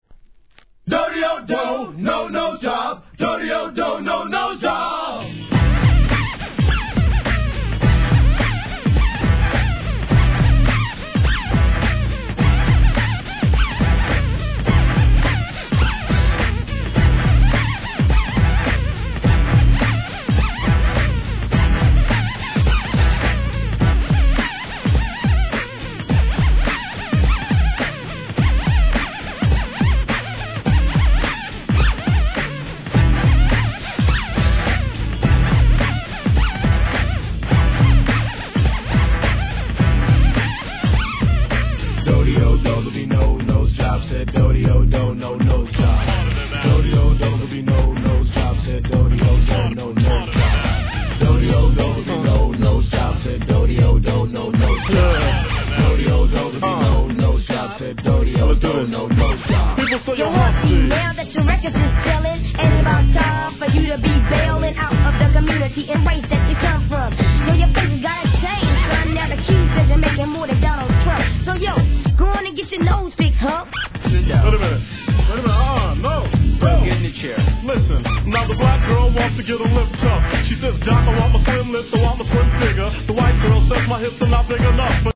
HIP HOP/R&B
歯がゆいスクラッチ音が印象的なトラックに、これまた鼻にかかるRAPが忘れられない2ndからのシリアスな傑作!!